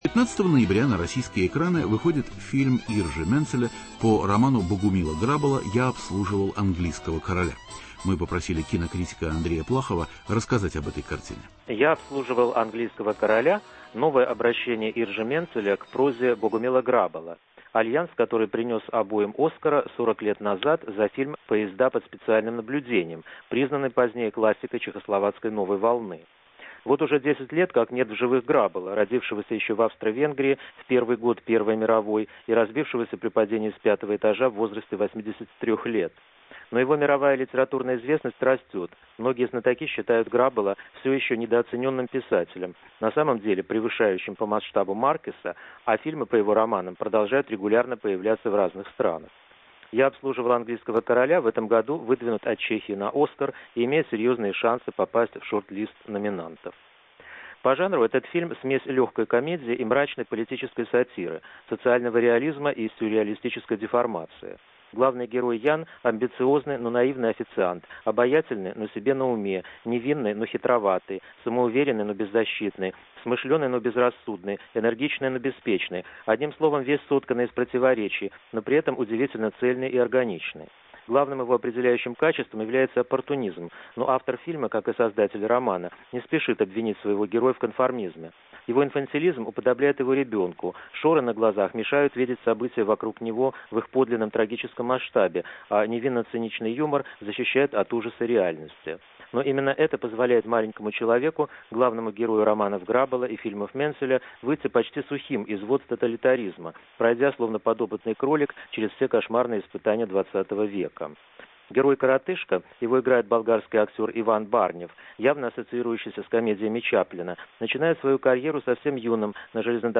Интервью с режиссером Иржи Менцелем о его новом фильме.